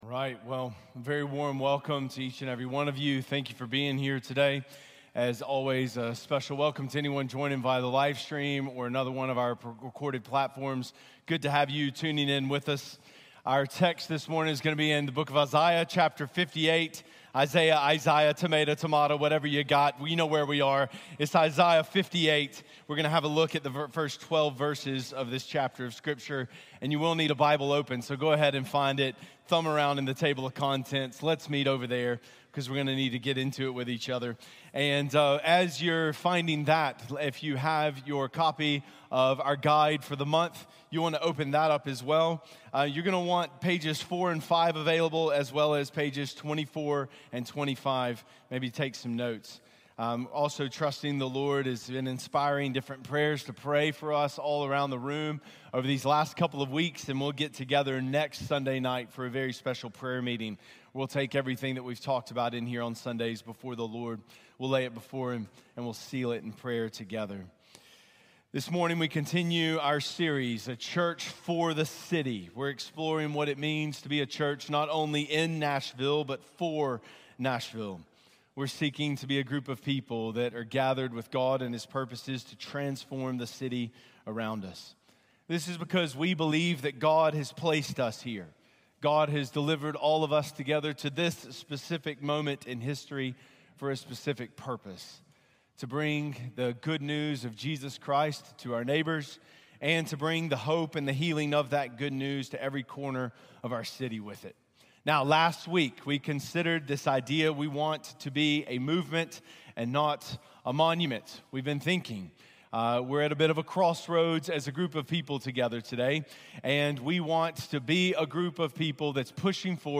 This sermon explores Isaiah 58. We are called to be repairers of the city, using our resources and creativity to bring about transformation that honors God and serves our neighbors.